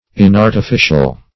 Inartificial \In*ar`ti*fi"cial\, a. [Pref. in- not + artificial:
inartificial.mp3